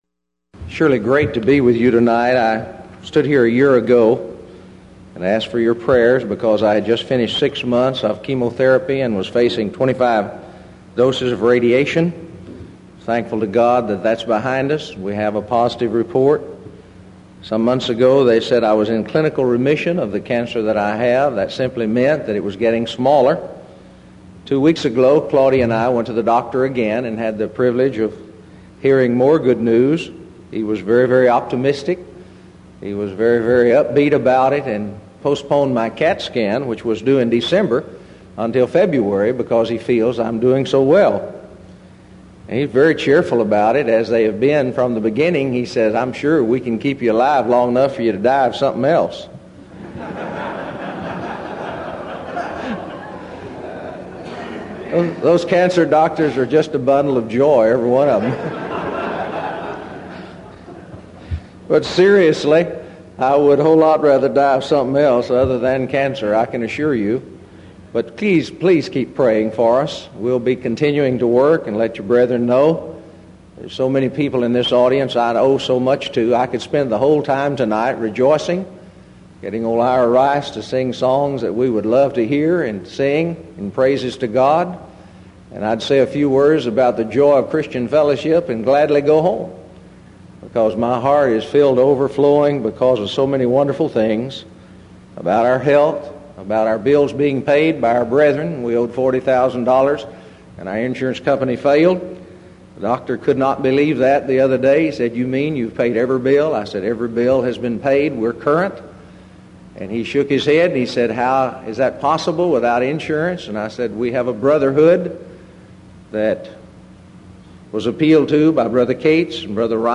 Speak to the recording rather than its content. Event: 1989 Denton Lectures